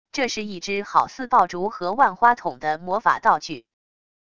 这是一支好似爆竹和万花筒的魔法道具wav音频